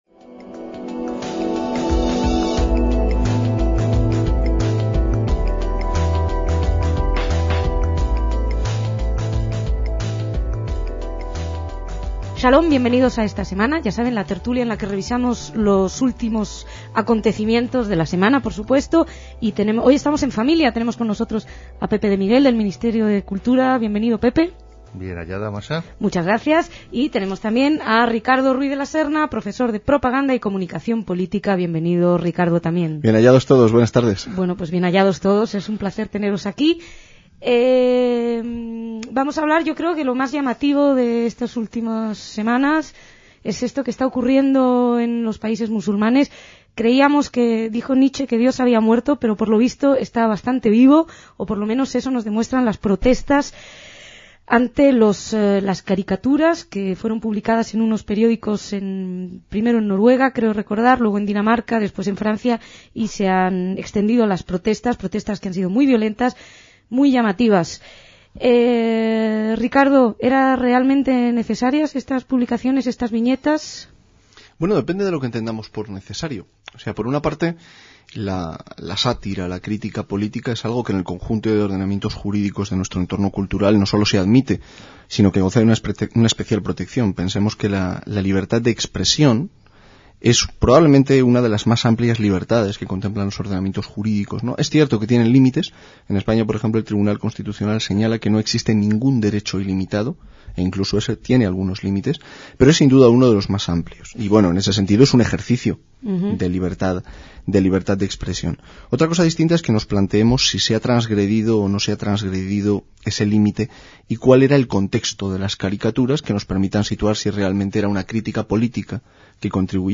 DECÍAMOS AYER (4/2/2006) - Tras las elecciones generales palestinas del 25 de enero de 2006, Hamás obtuvo la mayoría absoluta y fue el pistoletazo de salida para sus aspiraciones, no sólo de gobierno, sino de expulsión de los judíos del territorio de Israel, tema que, junto a otros, analizaron los invitados a esta tertulia.